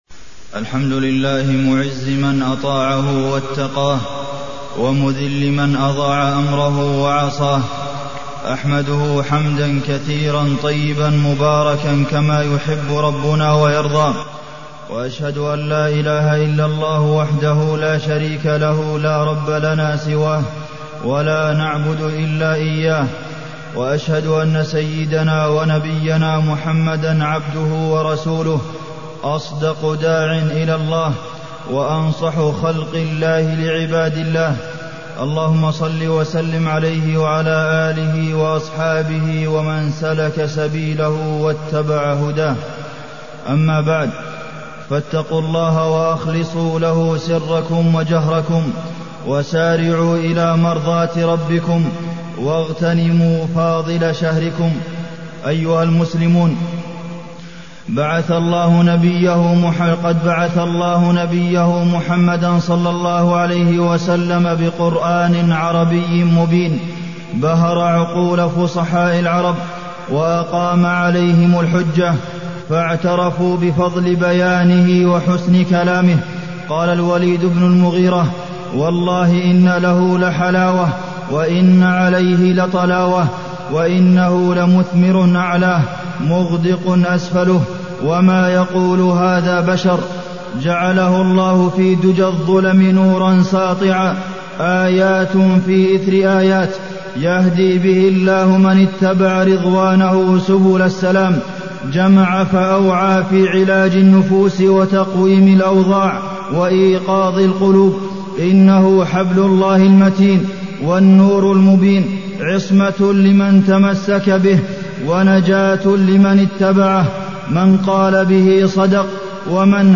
تاريخ النشر ١٦ رمضان ١٤٢٠ هـ المكان: المسجد النبوي الشيخ: فضيلة الشيخ د. عبدالمحسن بن محمد القاسم فضيلة الشيخ د. عبدالمحسن بن محمد القاسم فضل القرآن الكريم